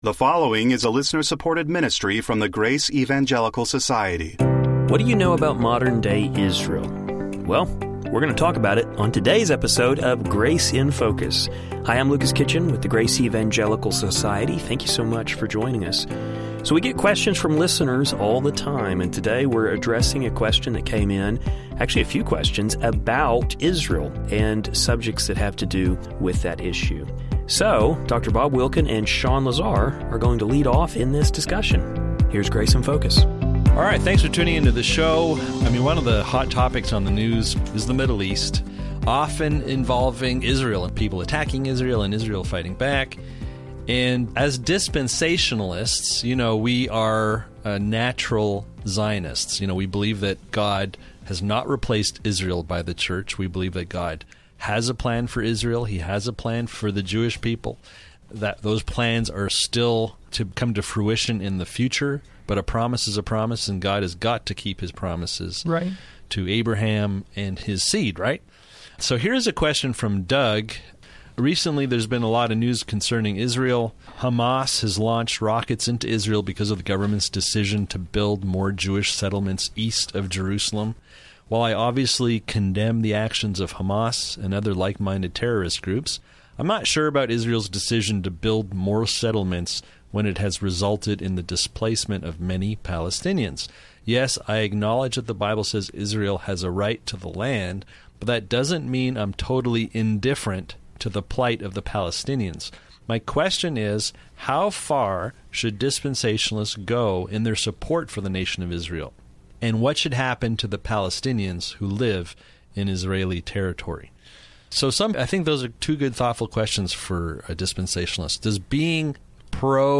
We hope you enjoy this discussion.